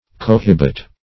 Search Result for " cohibit" : The Collaborative International Dictionary of English v.0.48: Cohibit \Co*hib"it\, v. t. [imp.